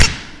Playersmacked Sound Effect
playersmacked.mp3